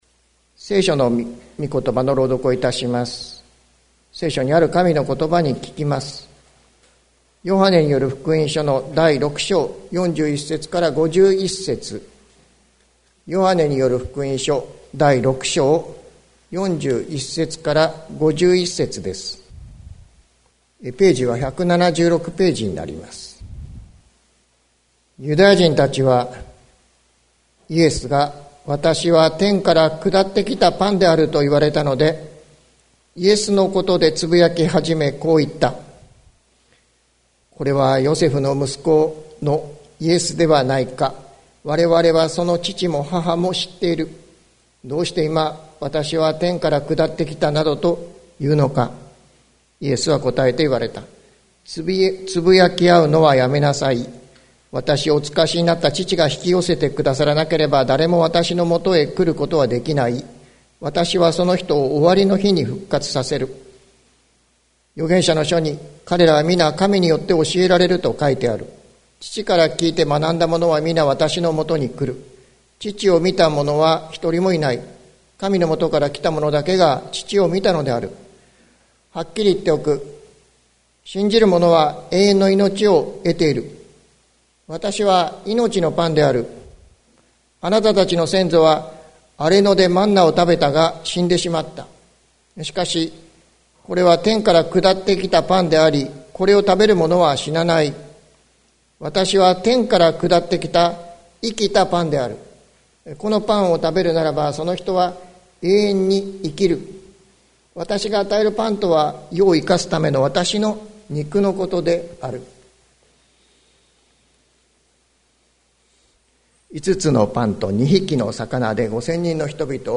2022年05月15日朝の礼拝「神がわたしたちを引き寄せられる」関キリスト教会
説教アーカイブ。